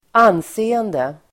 Uttal: [²'an:se:ende]